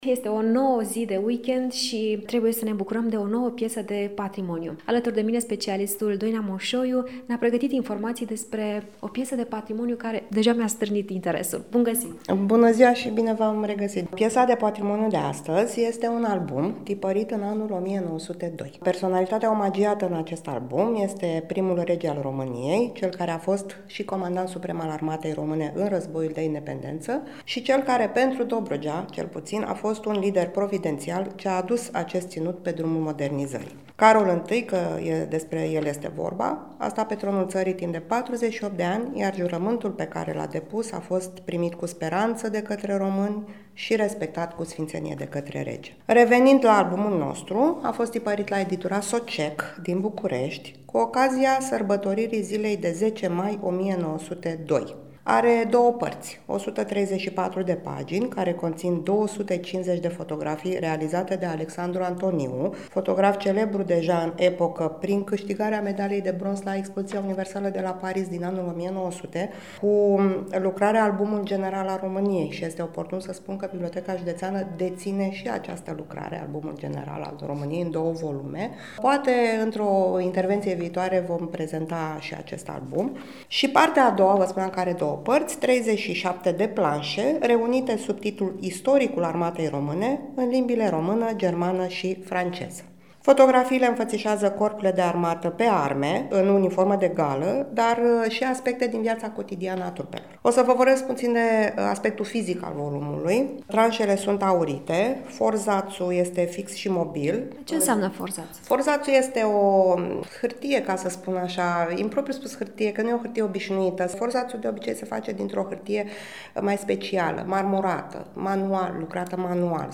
Bibliotecarul